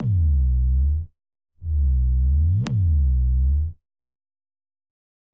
描述：来自灵魂唱片的谷物东西
声道立体声